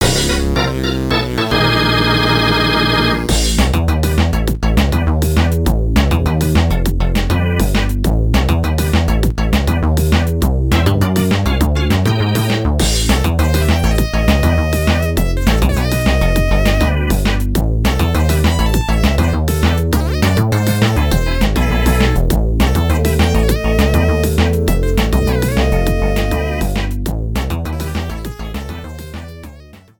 Cropped to 30 seconds, fade out added